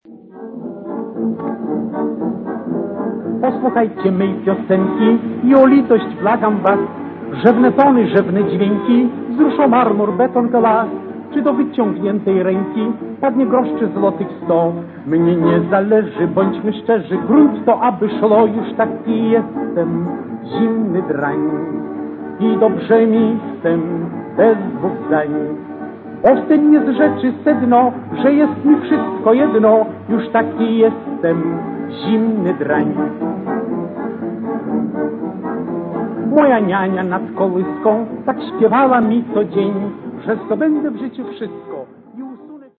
piosenka